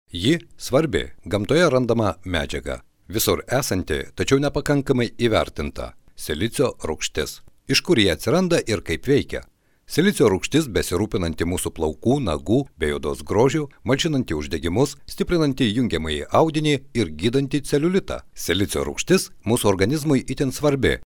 Lithuanian male voiceover